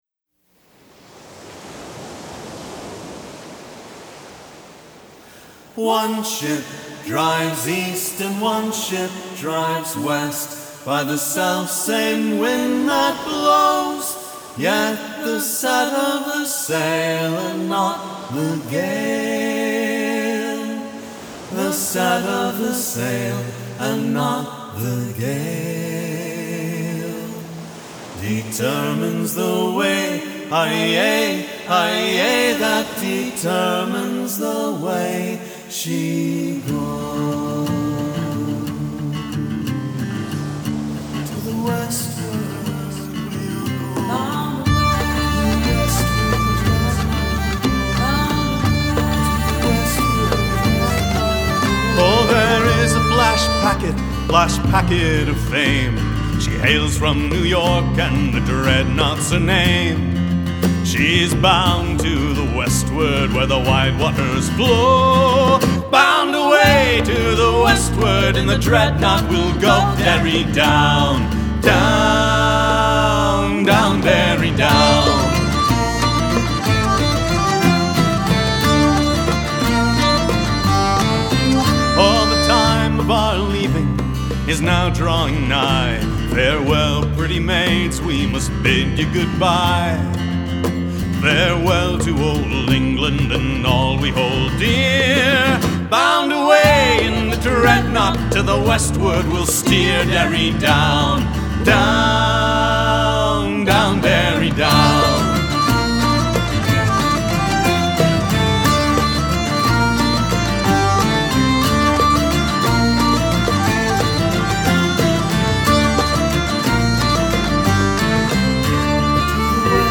The Dreadnaught is a traditional 'forebitter' or sailors' leisure song - usually sung while at rest, rather than a 'shanty' which was used to mark rhythm for a shipboard task.
Our arrangement incorporates a round based on a poem by Ella Wheeler Wilcox and an original tune on the hurdy-gurdy.